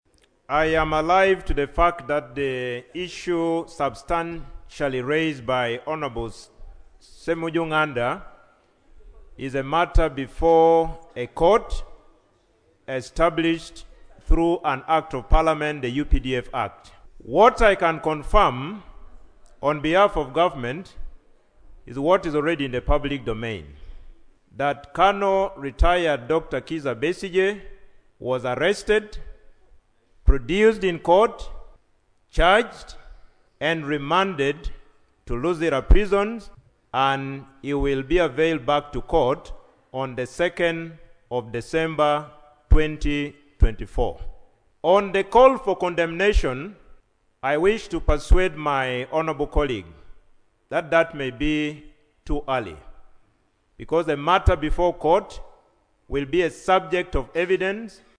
During the plenary sitting on Tuesday, 26 November 2024, legislators questioned the country's adherence to international and regional laws following the arrest of the Opposition leader.
However, the Government Chief Whip, Hon. Denis Hamson Obua who said the statement would be brought on Tuesday, 03 December 2024, cautioned MPs against premature condemnation.